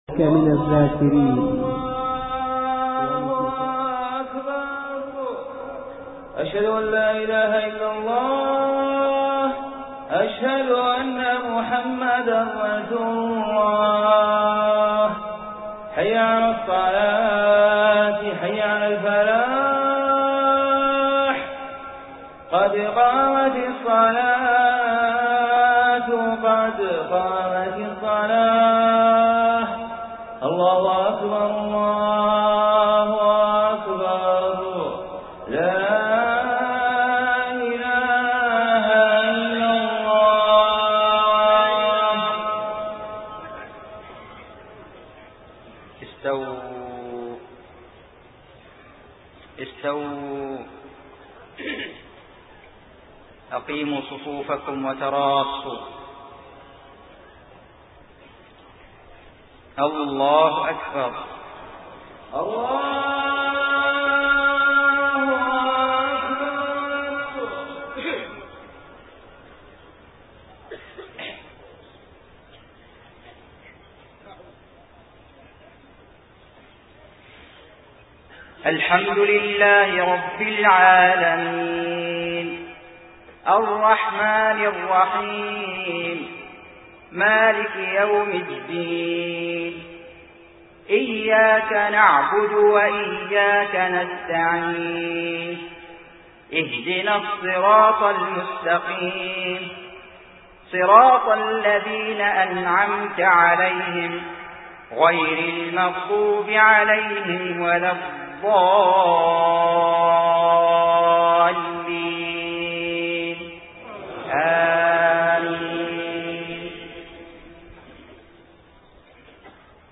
صلاة المغرب 6 محرم 1429هـ سورة الانفطار كاملة > 1429 🕋 > الفروض - تلاوات الحرمين